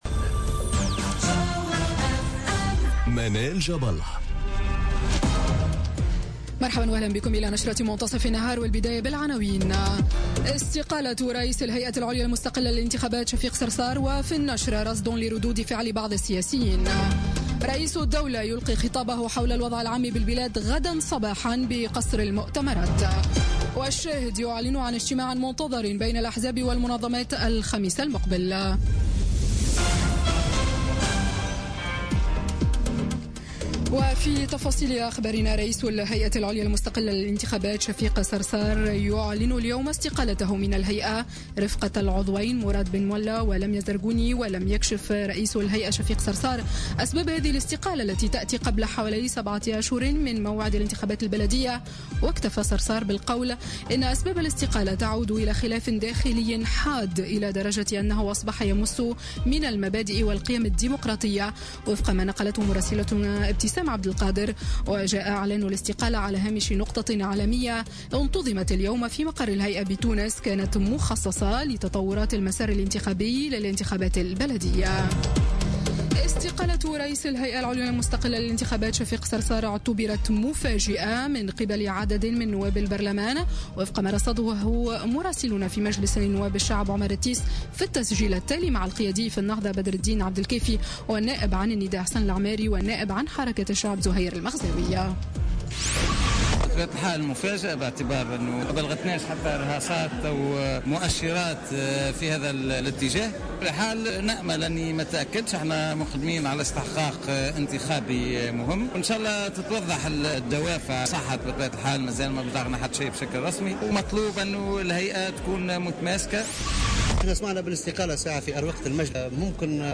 نشرة أخبار منتصف النهار ليوم الثلاثاء 9 ماي 2017